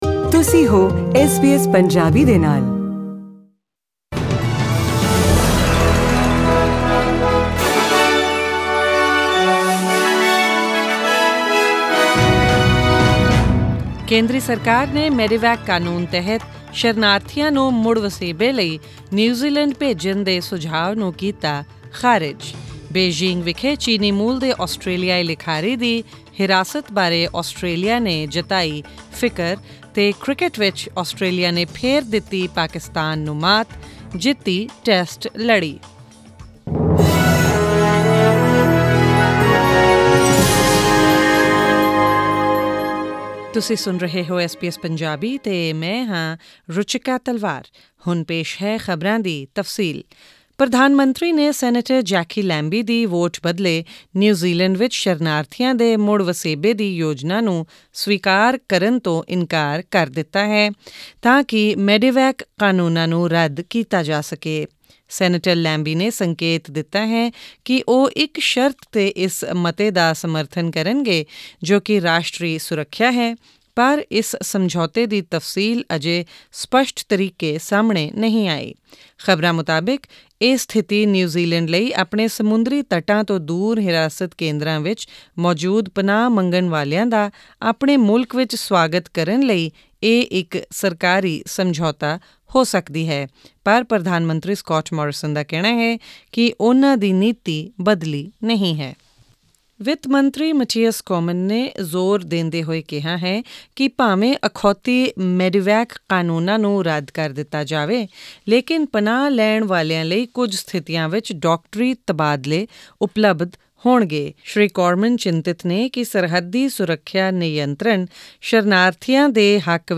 SBS Punjabi News: December 2, 2019